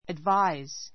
ədváiz